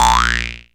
boing.wav